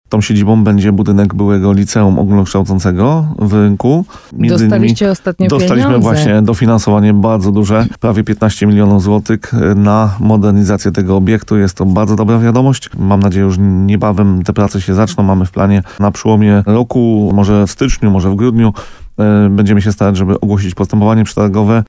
Jak mówił burmistrz Paweł Fyda w programie Słowo za Słowo na antenie radia RDN Nowy, przystosowany zostanie zabytkowy budynek w centrum miasta.